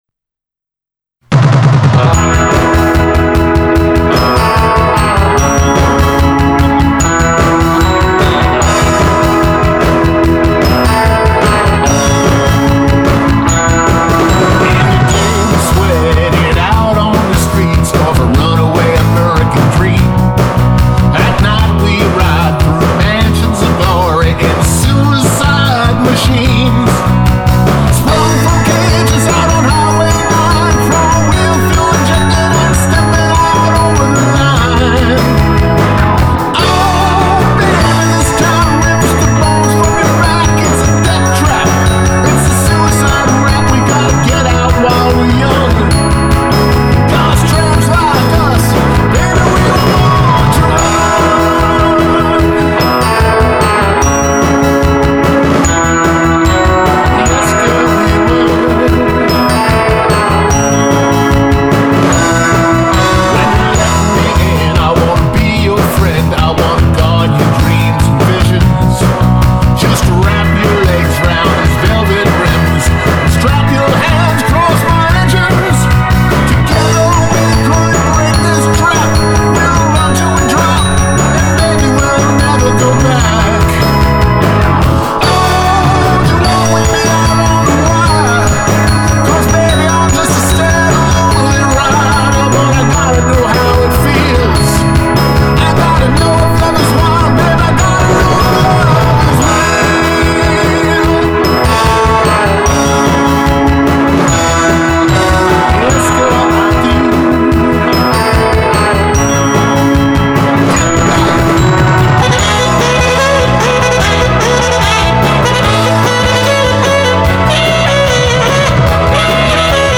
Classic Rock
played the sax on this number.